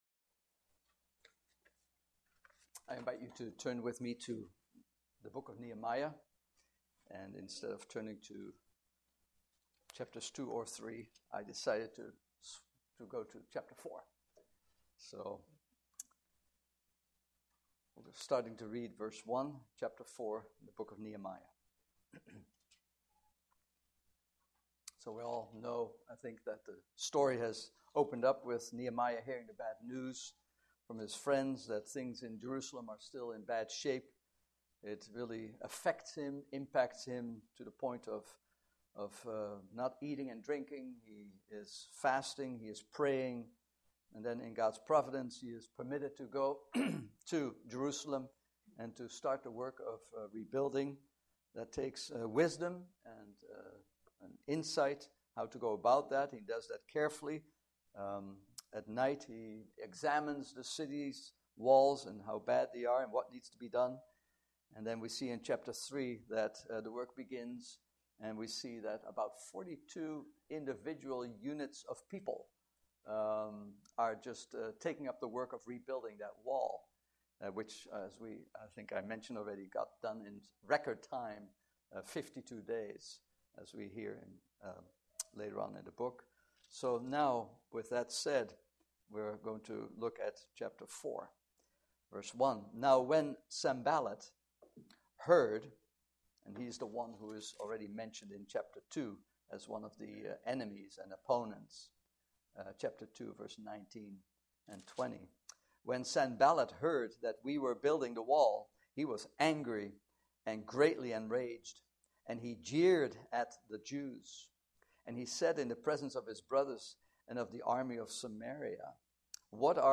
Passage: Nehemiah 4:1-23 Service Type: Morning Service